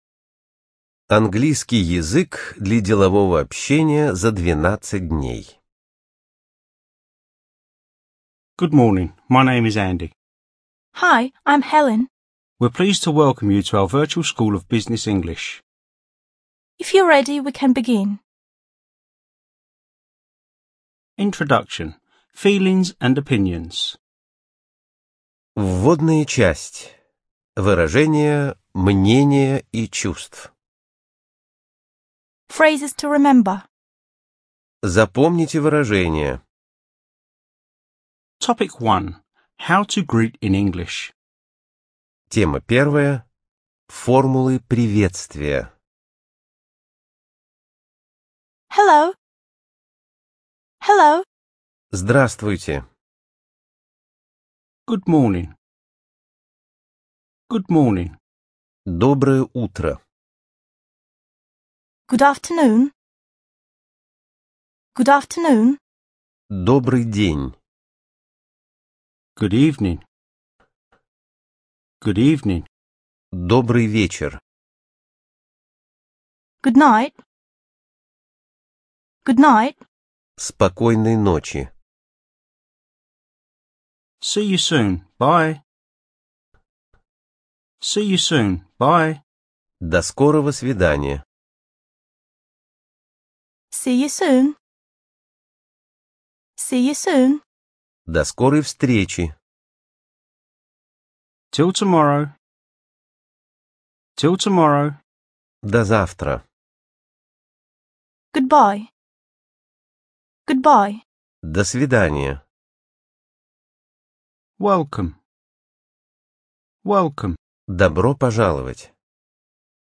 АвторАудиопособия
Студия звукозаписиРоссийская государственная библиотека для слепых